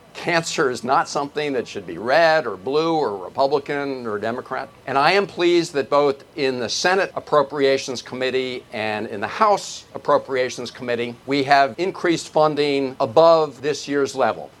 Held on the Mall in Washington, D.C., the rally featured remarks from Maryland Senator Chris Van Hollen who lost his mother to cancer 18 years ago. The Senator said that investments in research help to prolong lives and is something that cuts across party lines…